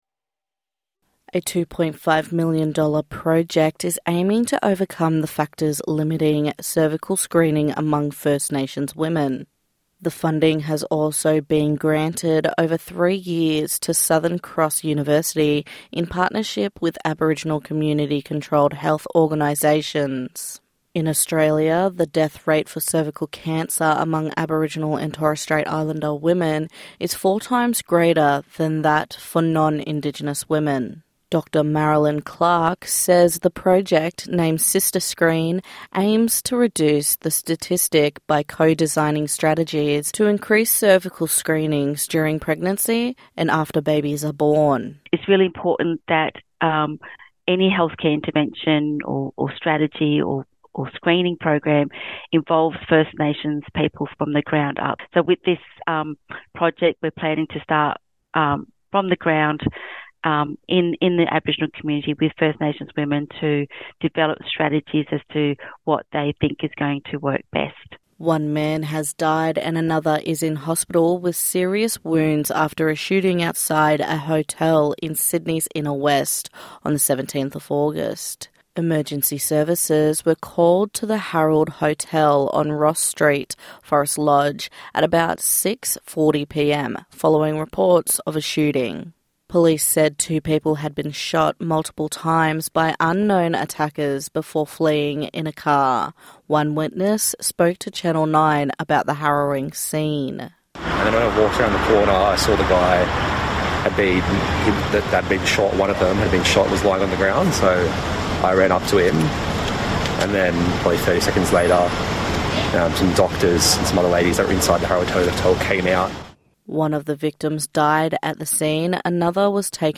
NITV Radio News - 18/08/2025